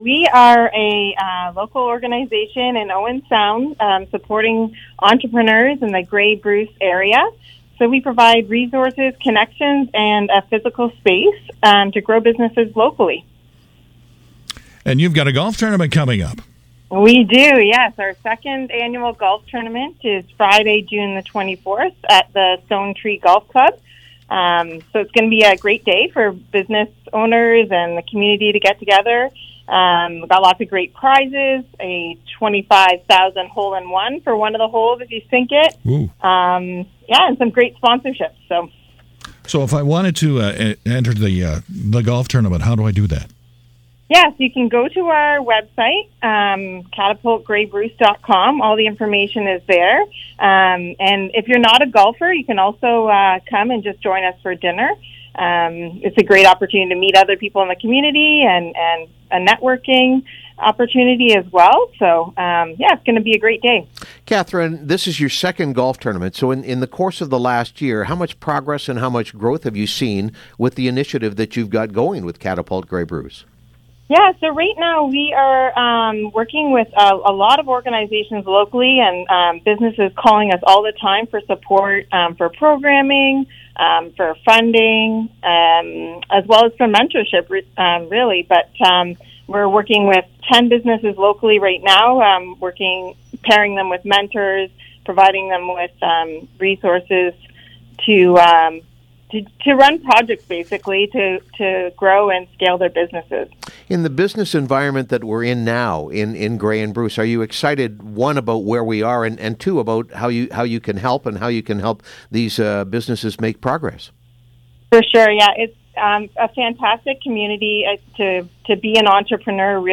Catapult Grey Bruce 2nd Golf Tournament Nears: Interview